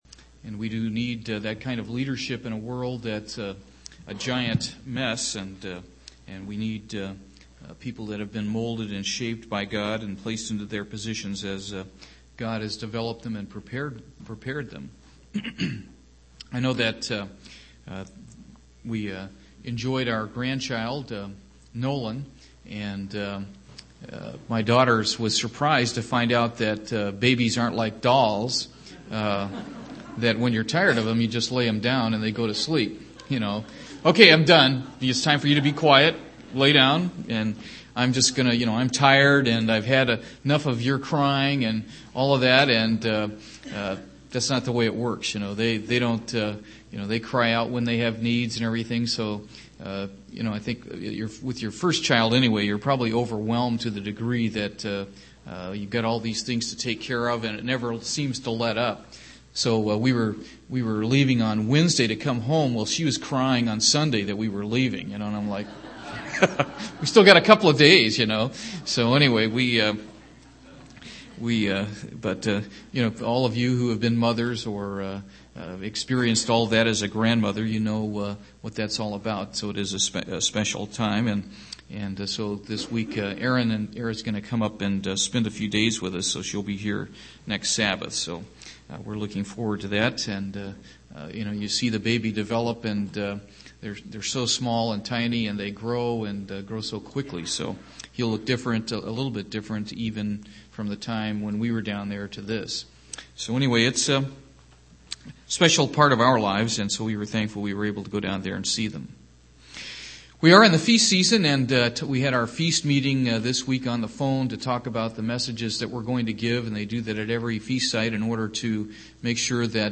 Examine the Kingdom of God to find out what it is and what it isn't. UCG Sermon Studying the bible?